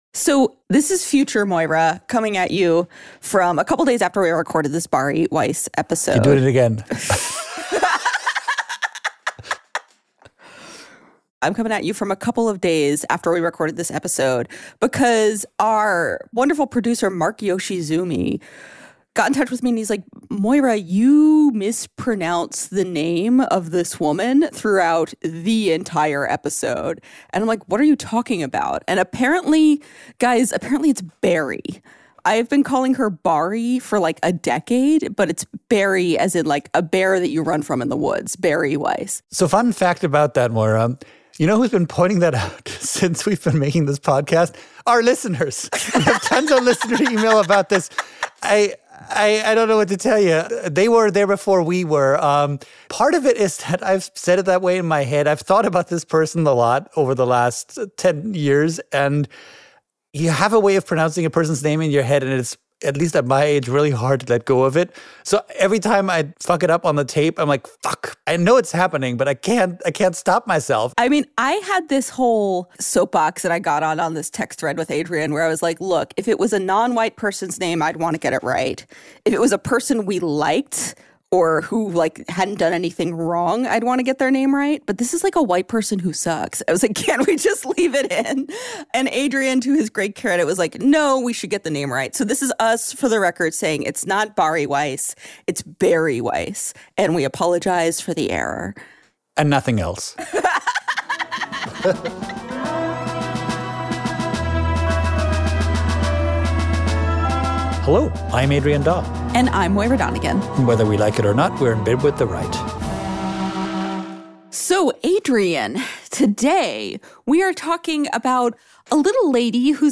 CONTENT NOTE: Yes, we know we're mispronouncing her name.